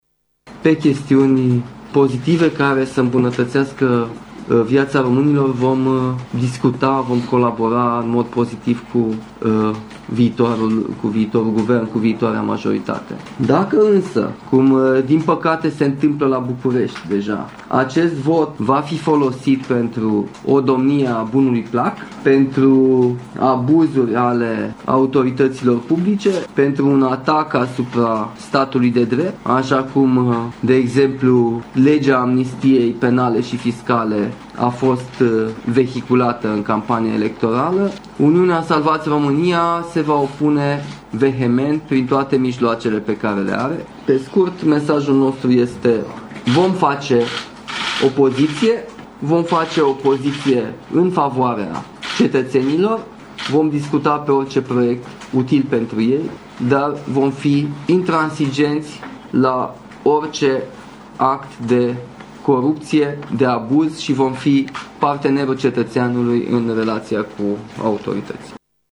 Membrii formațiunii vor fi intransigenți cu orice act de corupție, a mai spus liderul USR într-o conferință de presă: